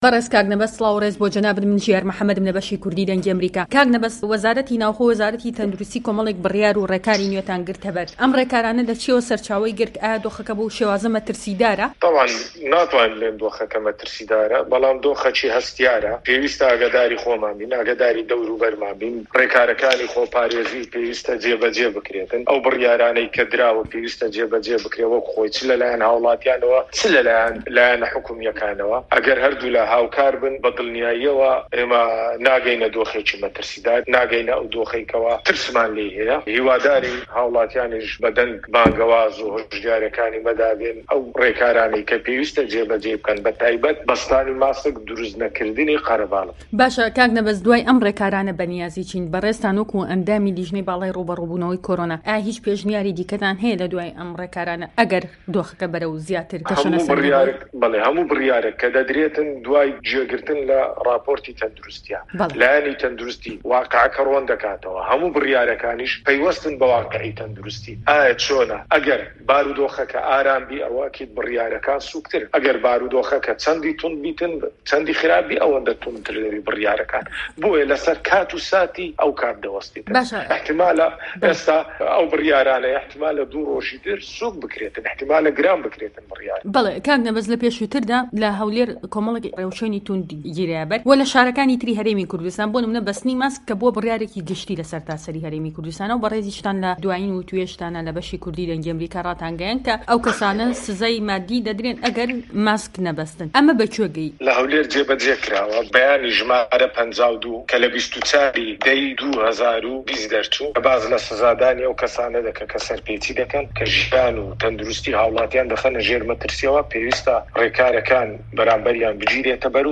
نه‌به‌ز عه‌بدولحه‌مید-قایمقامی هه‌ولێر‌